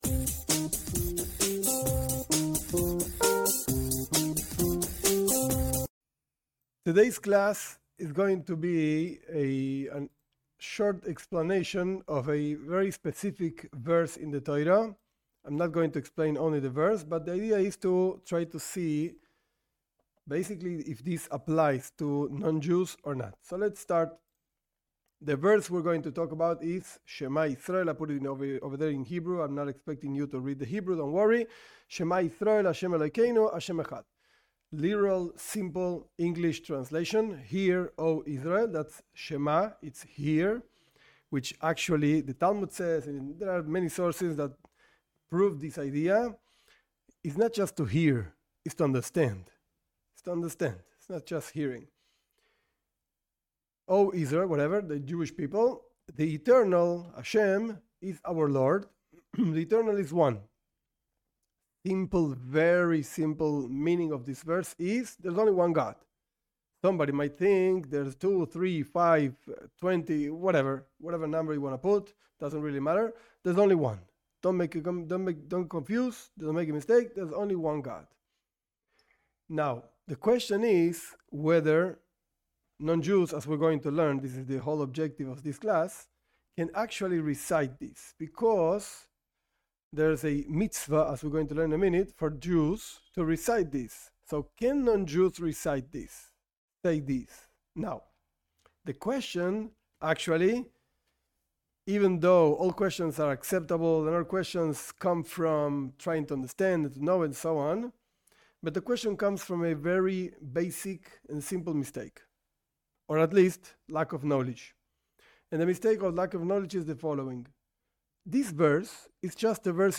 This class explains, with sources, whether non Jews can recite the Shema Israel verse(s) in the Torah. This particular verse talks about the unity and uniqueness of God.